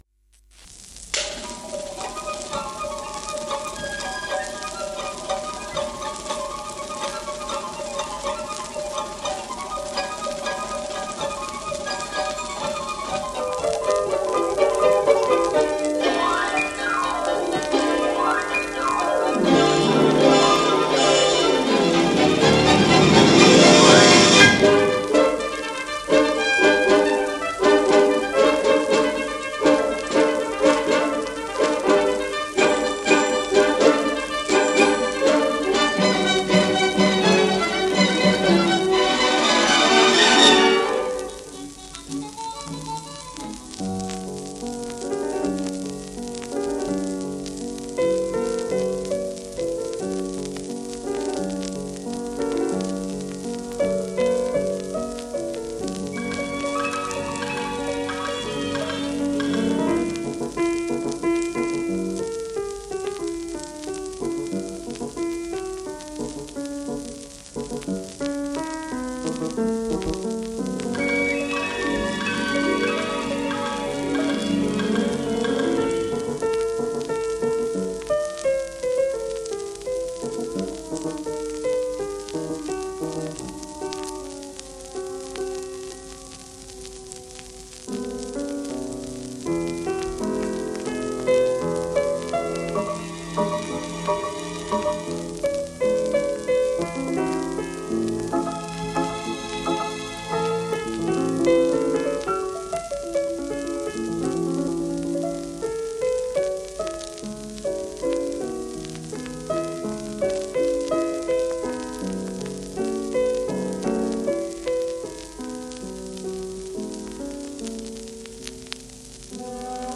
1949年録音